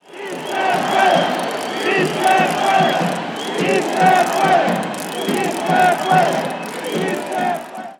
Demonstration
Sie wird meistens mit Marschgesängen durchgeführt.